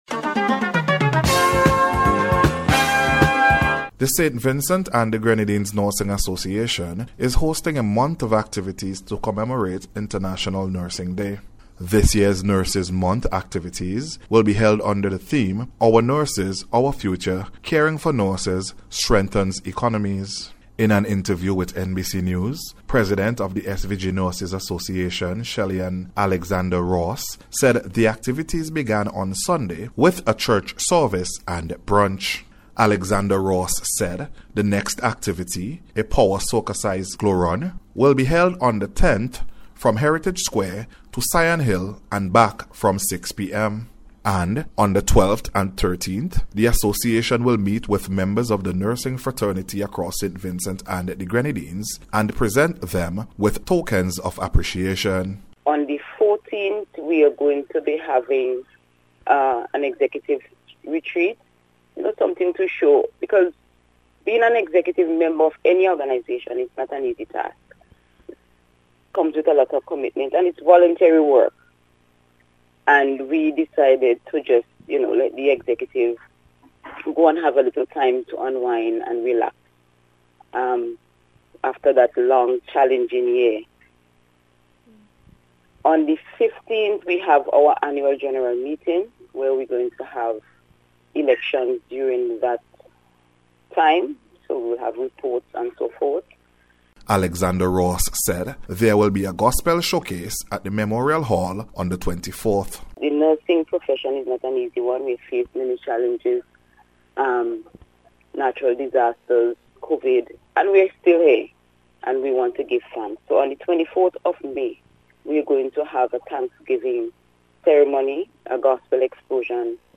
NURSING-WEEK-ACITIVITES-REPORT.mp3